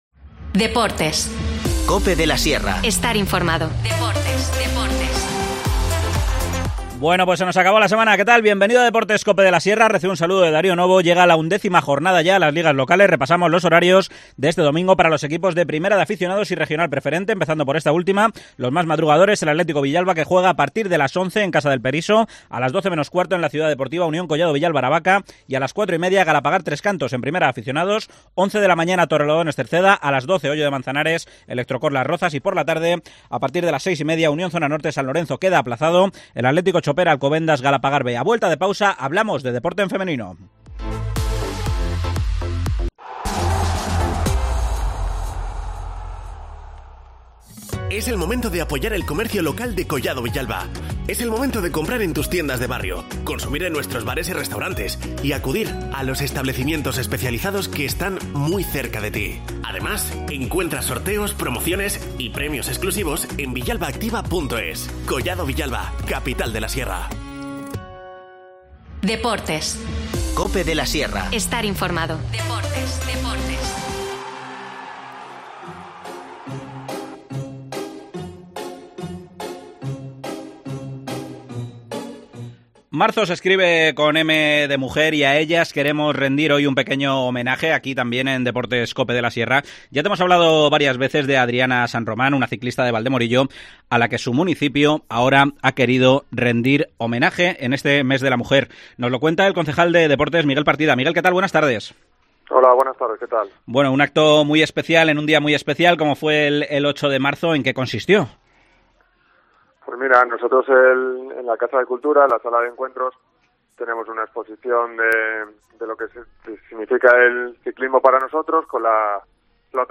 Nos cuenta los detalles el edil de Deportes, Miguel Partida.